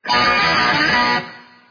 Play Bad To The Bone Riff Sped Up soundboard button | Soundboardly
bad-to-the-bone-riff-sped-up.mp3